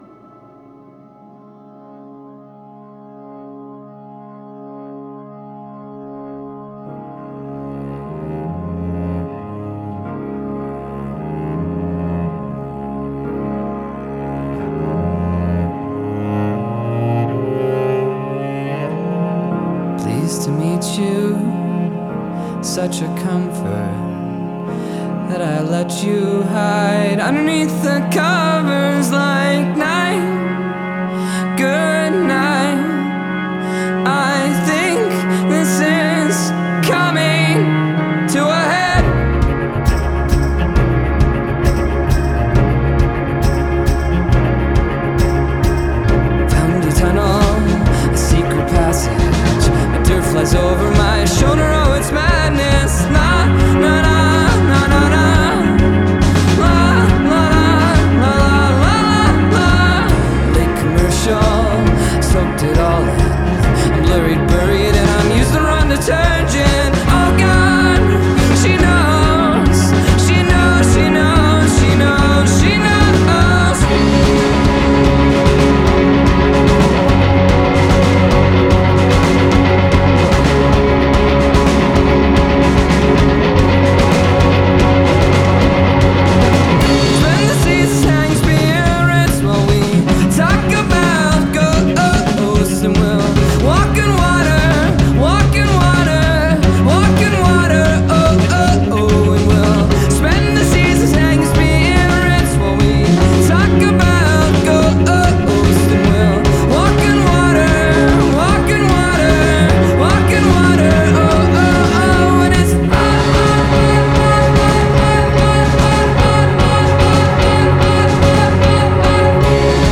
Canadian indie band